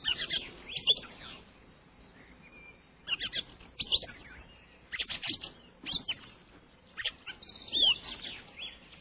169ambient01.wav